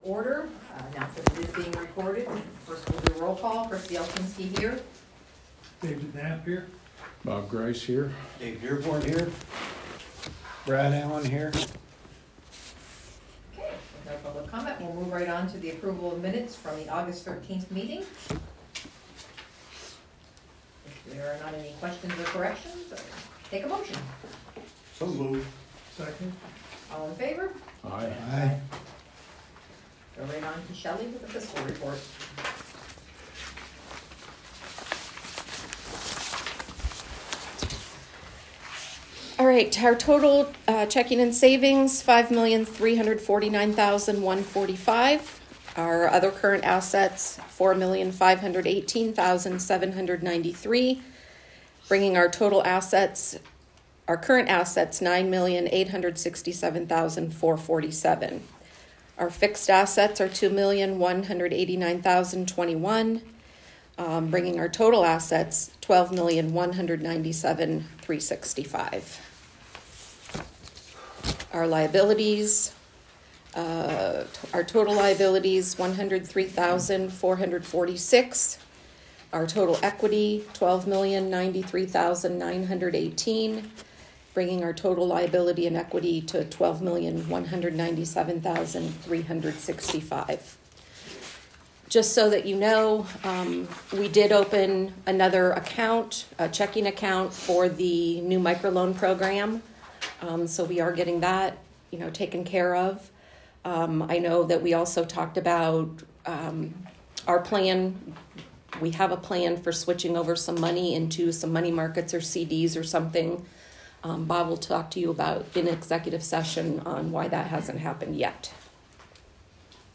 Meeting Audio